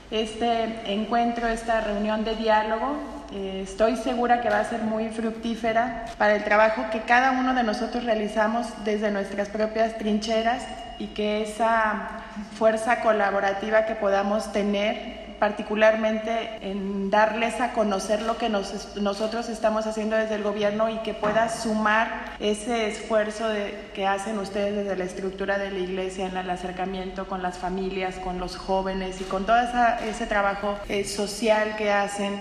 Lorena Alfaro García – Presidenta Municipal